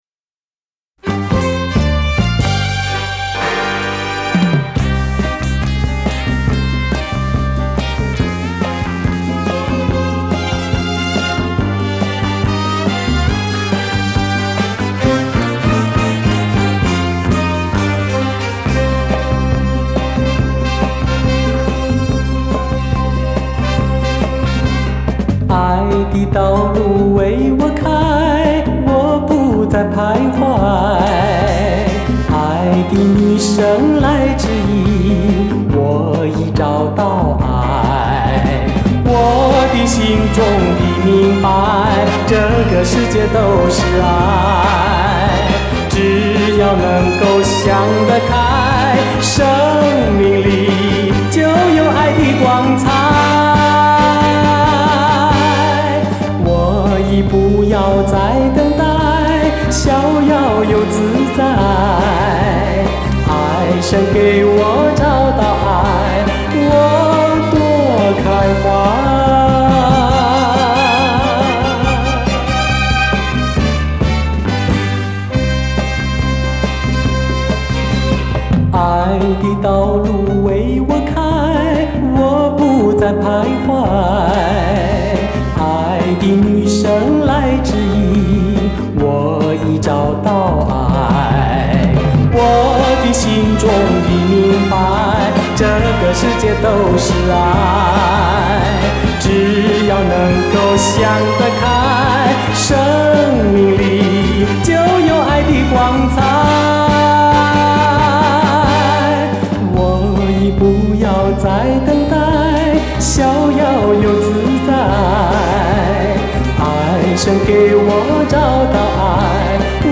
[华语流行]